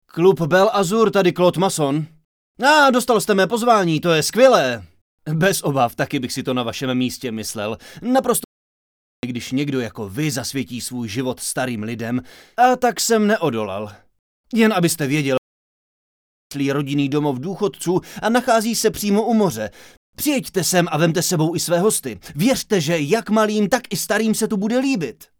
Dabing: